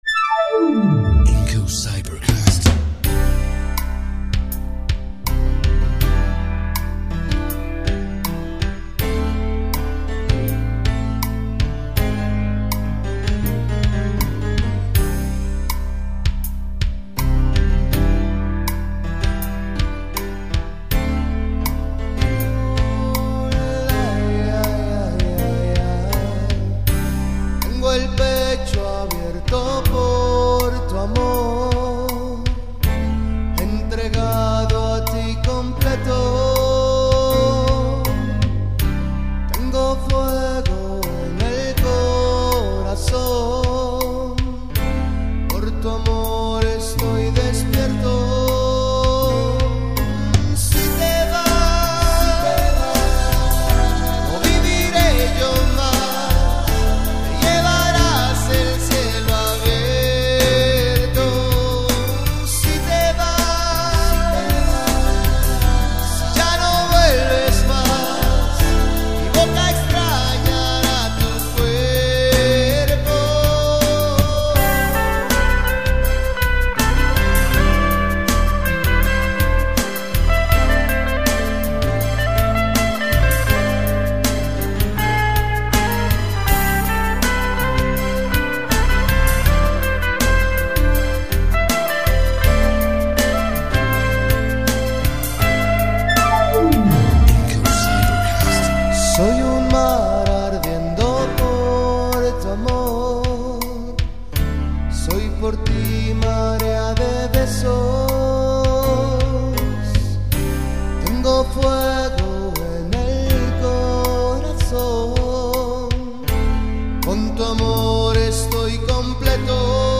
Voz y Coros